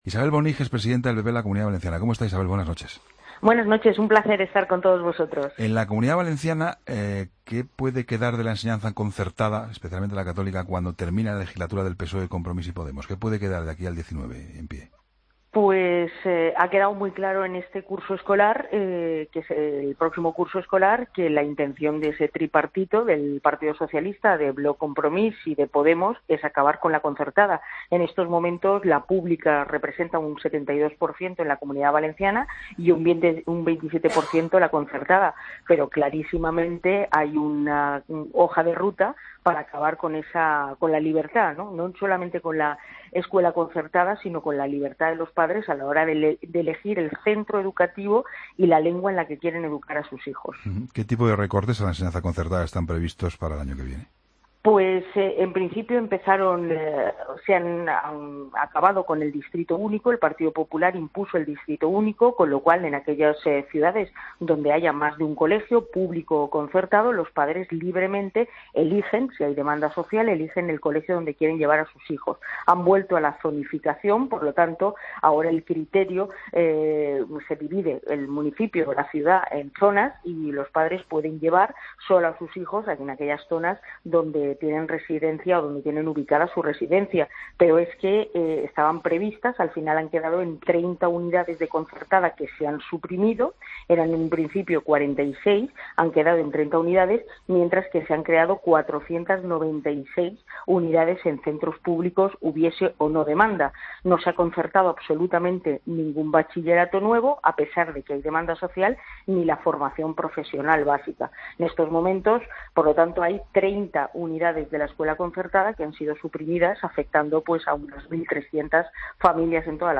Entrevista a la presidenta del PP en Valencia, Isabel Boning, en 'La Linterna'